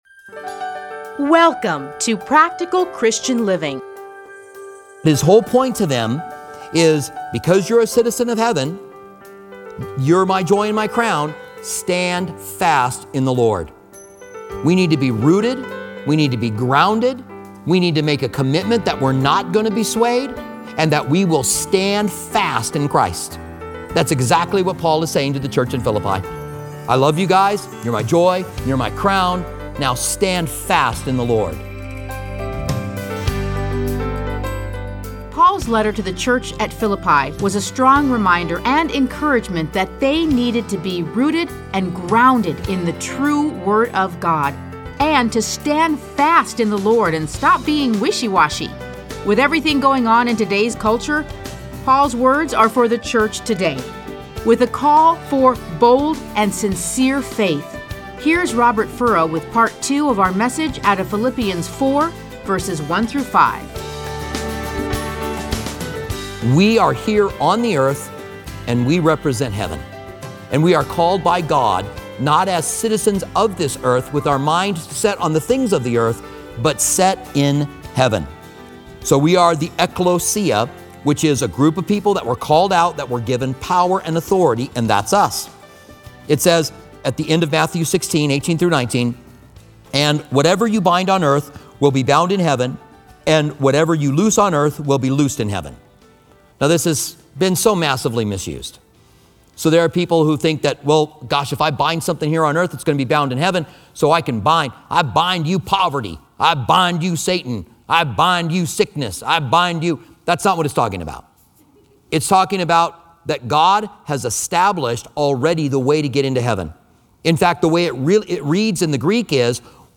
Listen to a teaching from A Study in Philippians 4:1-5.